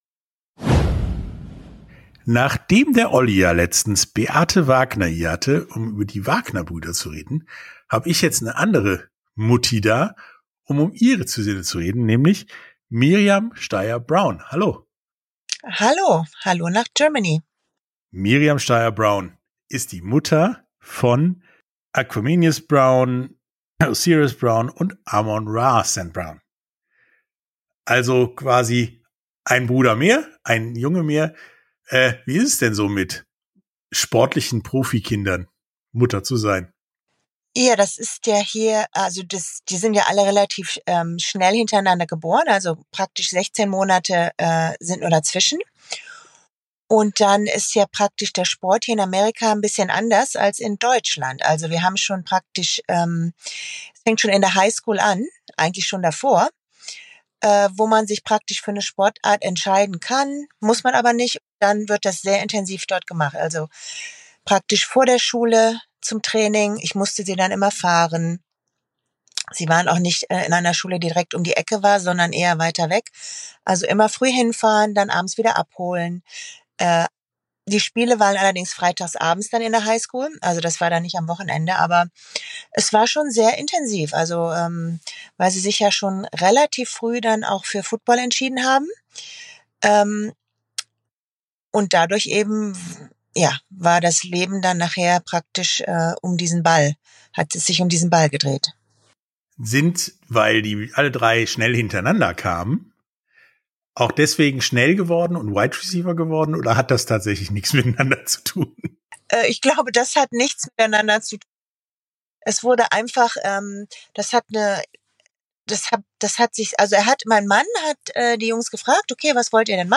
Interviews in voller Länge